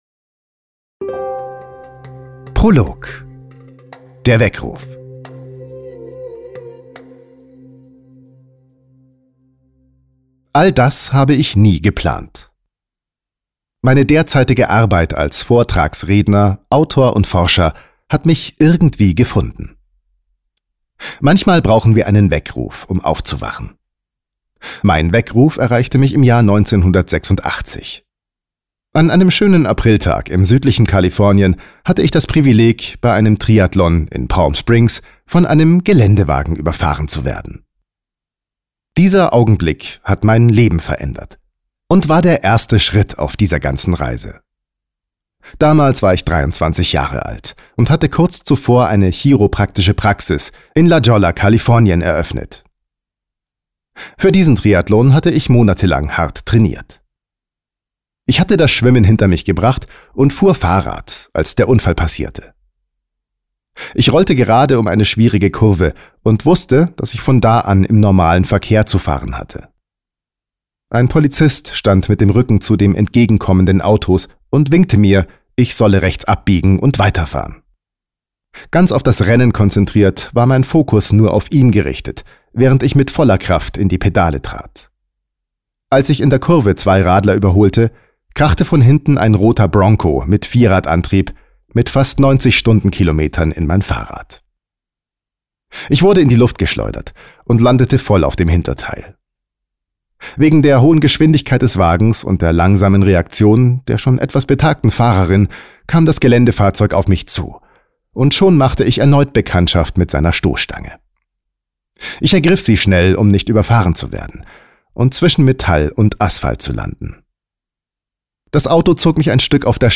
Du bist das Placebo (Hörbuch download)